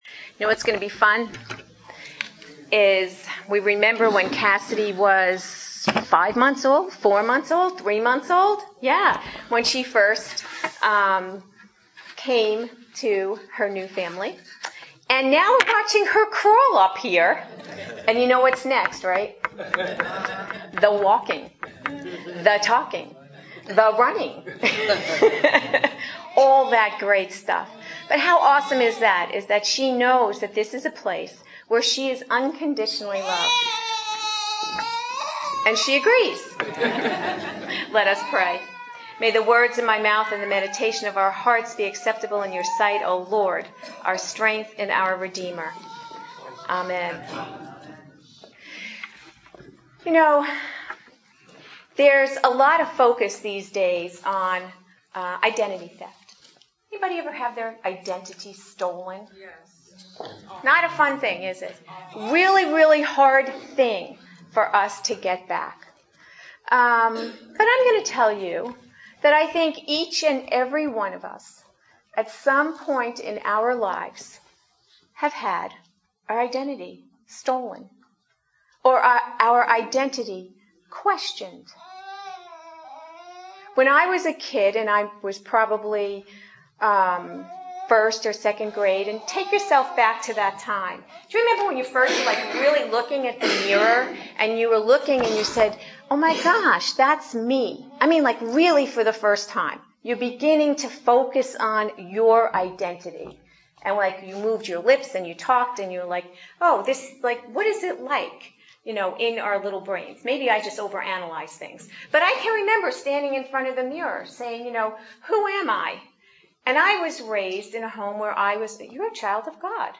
Series: Adult Sermons